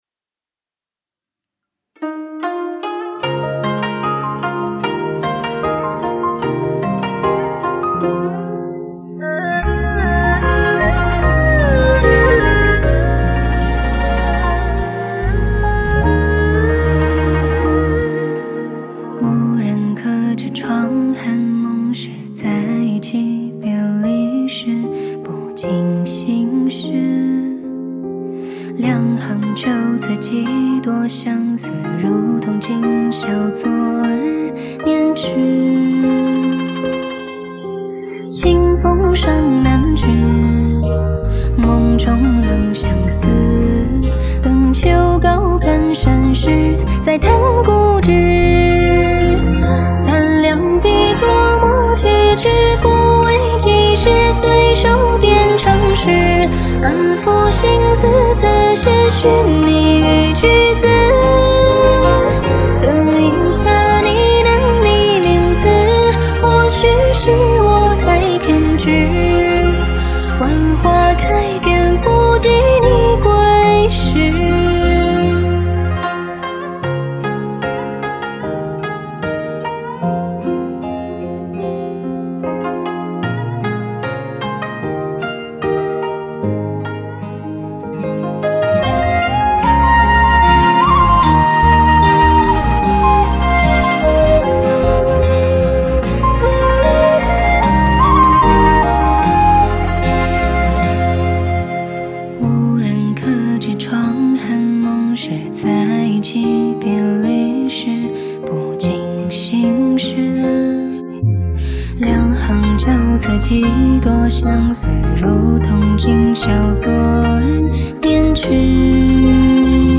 乐队/组合